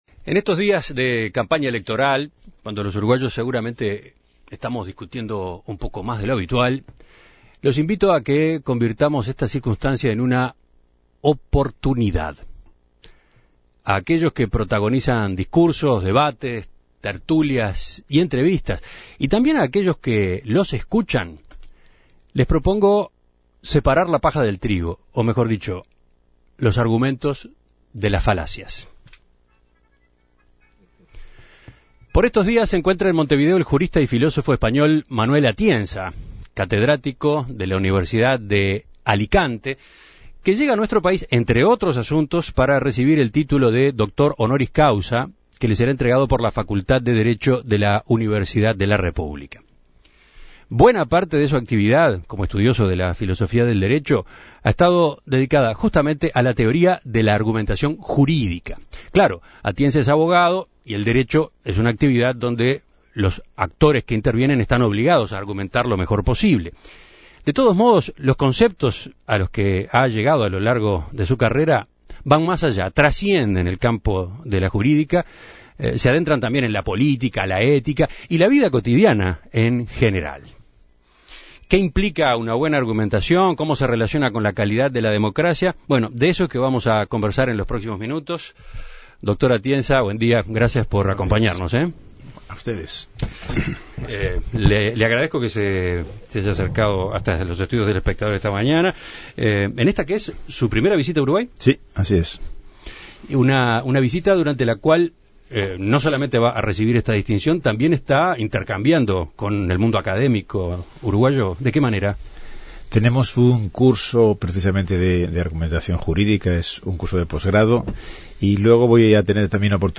En Perspectiva dialogó con Atienza, quien vino a recibir el título de Doctor Honoris Causa por la UdelaR. En la entrevista, el jurista destacó el rol de Carlos Vaz Ferreira en la teoría de la argumentación y señaló que los líderes políticos actuales "argumentan muy mal".